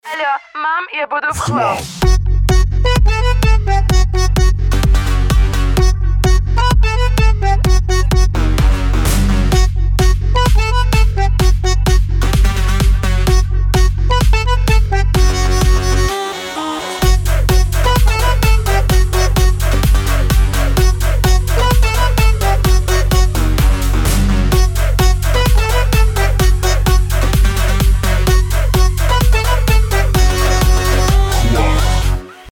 • Качество: 320, Stereo
зажигательные
веселые